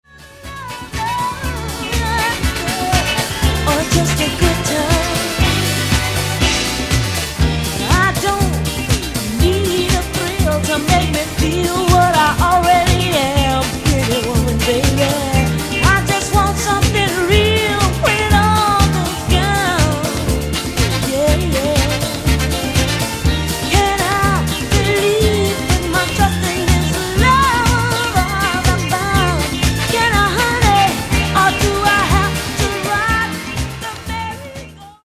Genere:   Funky | Soul